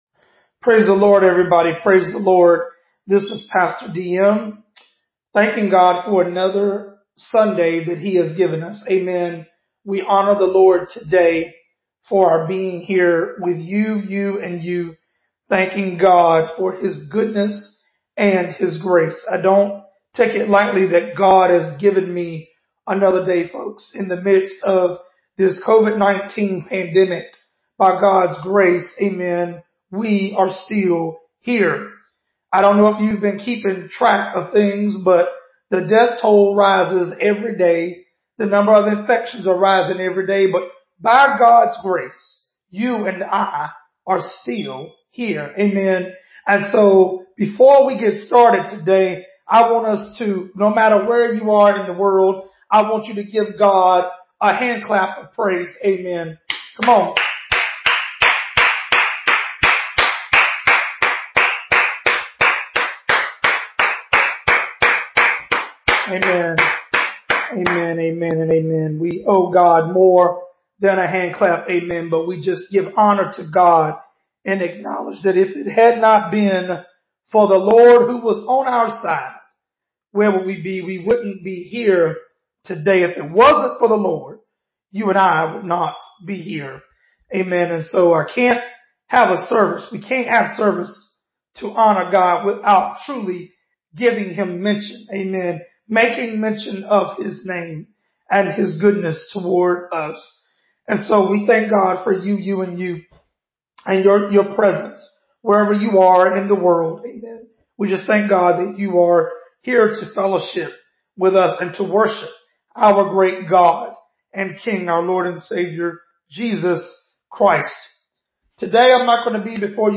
The Devil’s Dare: Luke 4:9-12 (Sermon)